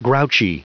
Prononciation du mot grouchy en anglais (fichier audio)
Prononciation du mot : grouchy